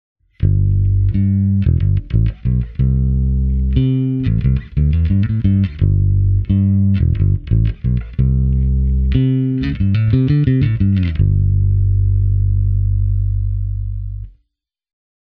Here are a few sound clips that I’ve recorded with my trusty Jazz Bass, going via my Sans Amp Bass Driver DI into Pro Tools.
My own Jazz Bass is a Japanese Standard-model from 1985, strung with a Rotosoundin Swing Bass -set.
fingerstyle/neck PU
jazz-bass_finger_neckpu.mp3